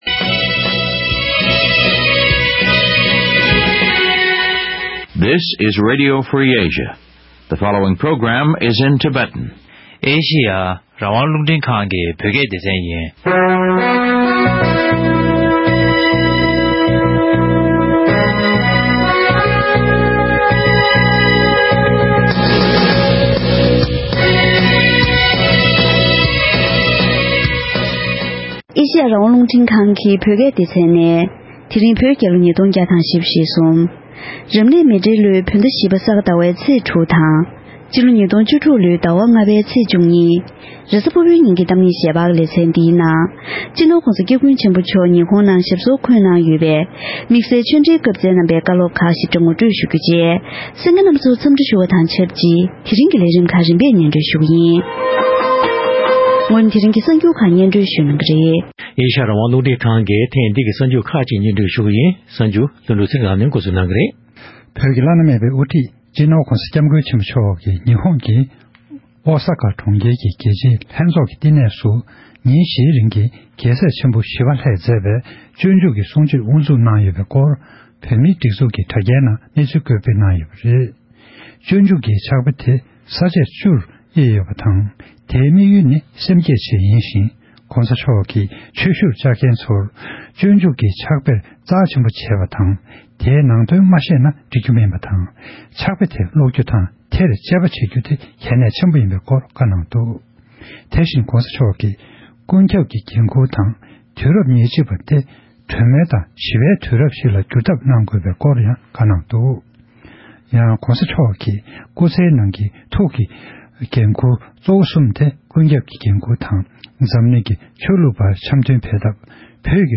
༄༅།།ད་རིང་གི་གཏམ་གླེང་ཞལ་པར་ལེ་ཚན་ནང་བོད་ཀྱི་བླ་ན་མེད་པའི་དབུ་ཁྲིད་སྤྱི་ནོར་༧གོང་ས་སྐྱབས་མགོན་ཆེན་པོ་མཆོག་ཉི་ཧོང་ལ་ཞབས་སོར་འཁོད་དེ་སྤྱོད་འཇུག་གི་གསུང་ཆོས་གནང་བཞིན་ཡོད་པ་ལྟར་༧གོང་ས་མཆོག་གི་བཀའ་སློབ་དང་། འབྲེལ་ཡོད་སྐོར་ལ་ཉི་ཧོང་བོད་ཀྱི་དོན་གཅོད་ལུང་རྟོགས་ལགས་སུ་གནས་འདྲི་ཞུས་པ་ཞིག་གསན་རོགས་གནང་།
གཏམ་གླེང་ཞལ་པར།